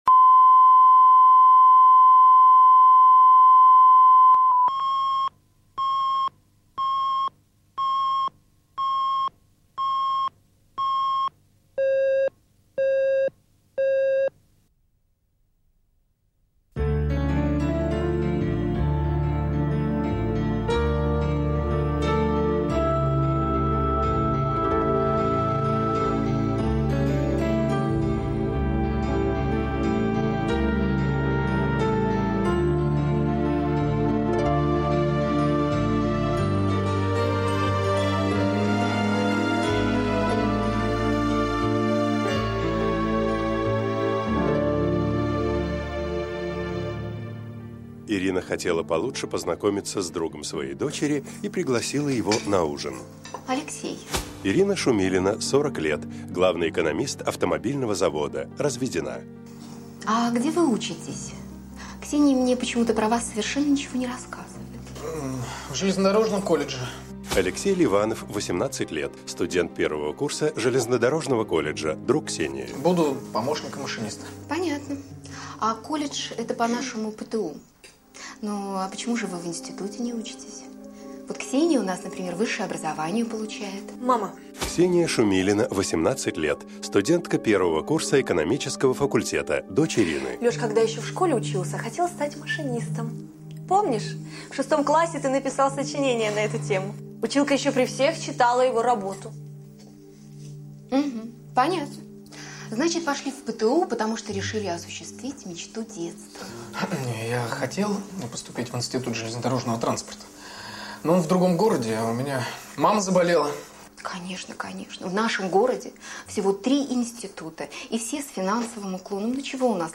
Аудиокнига Слишком простой